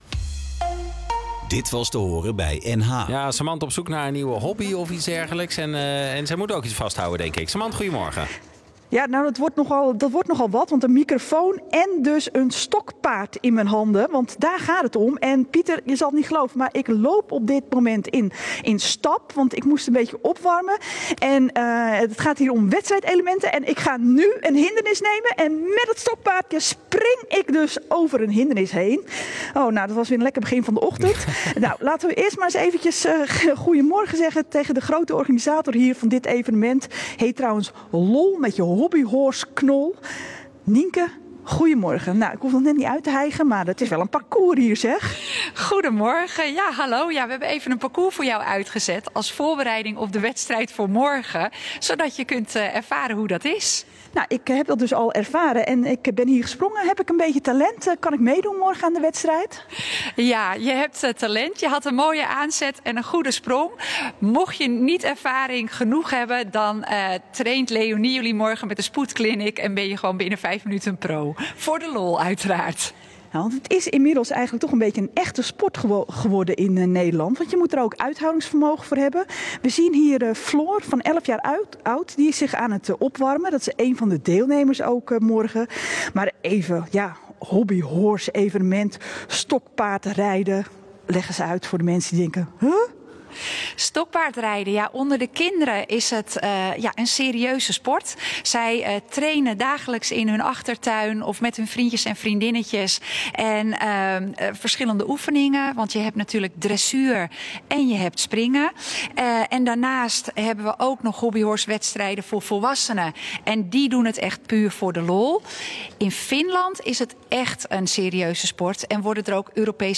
Radio NH live uitzending